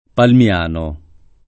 Palmiano [ palm L# no ]